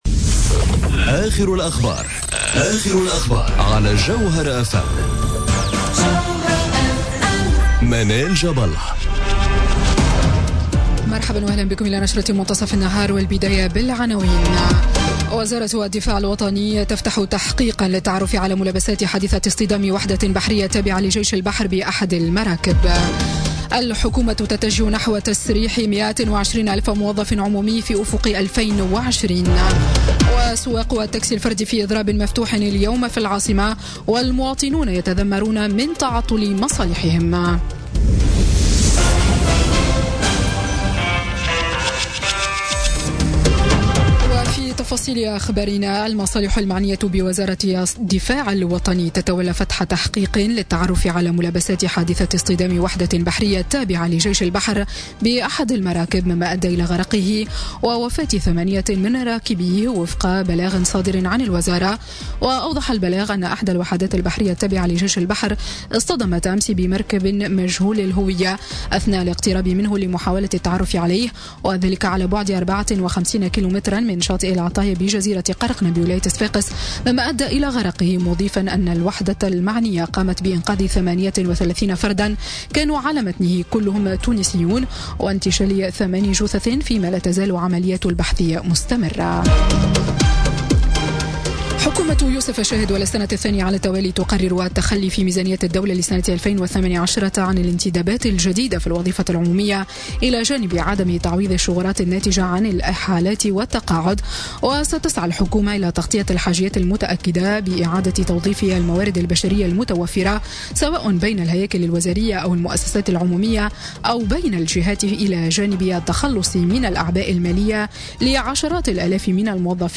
نشرة أخبار منتصف النهار ليوم الإثنين 9 أكتوبر 2017